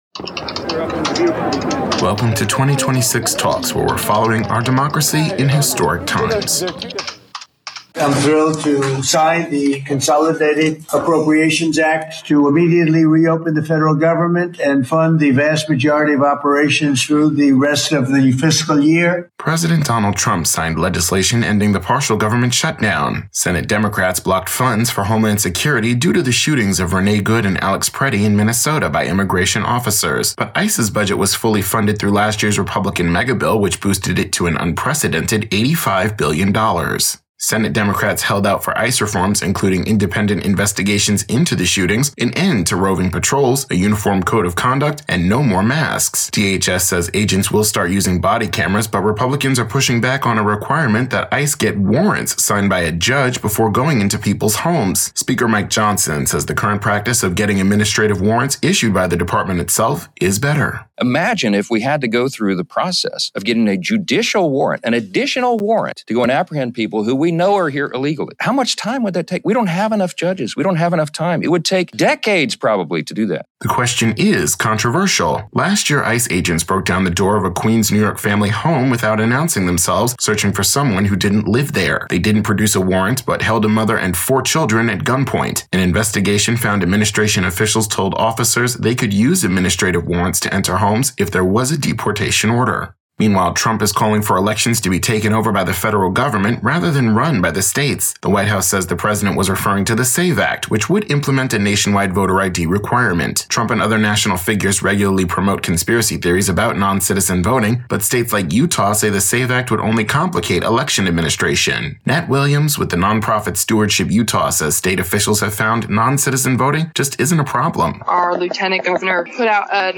Broadcast version